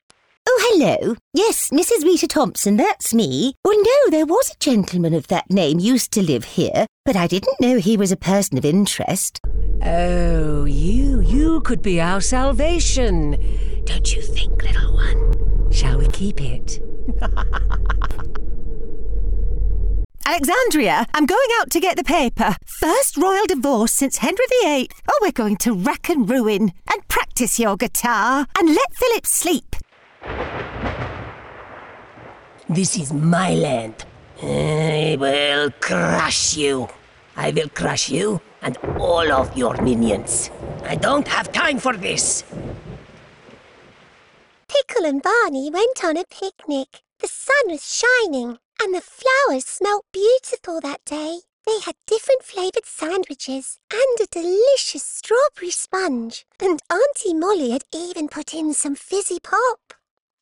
English (British)
Warm, Friendly, Versatile, Natural, Mature